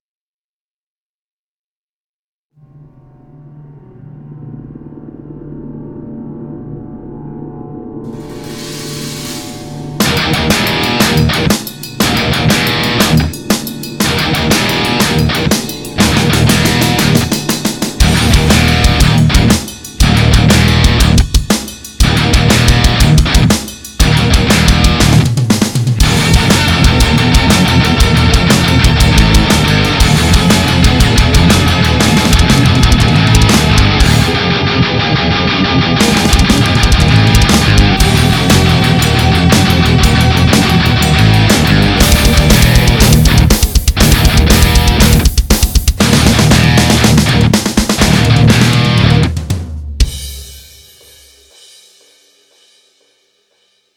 MetalLicks
AMPMetalLicks.mp3